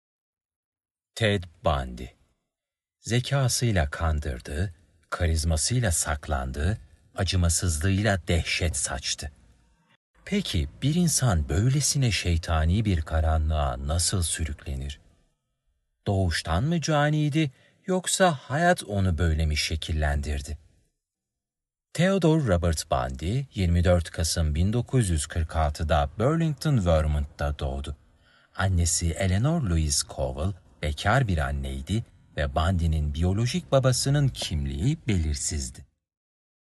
İngilizce - Kuzey Amerika Erkek | Genç Yetişkin Sunum / Eğitim
documentary voiceover